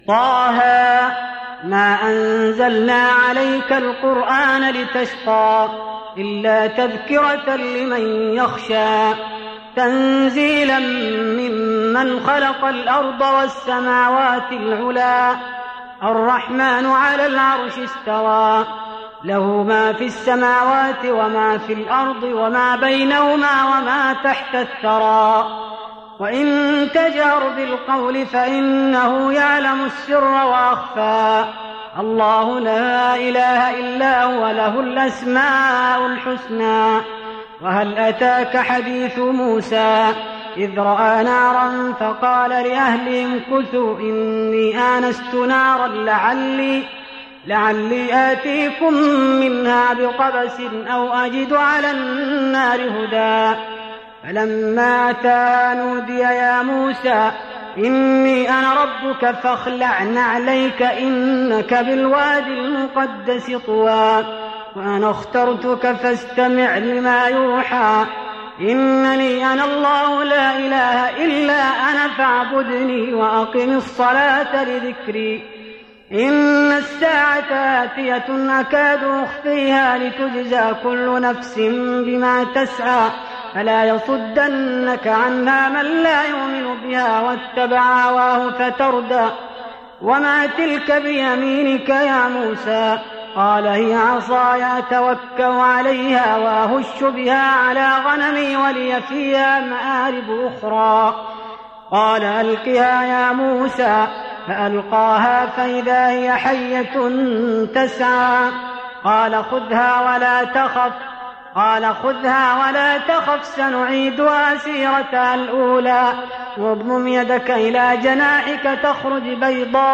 تهجد رمضان 1415هـ سورة طه (1-79) Tahajjud Ramadan 1415H from Surah Taa-Haa > تراويح الحرم النبوي عام 1415 🕌 > التراويح - تلاوات الحرمين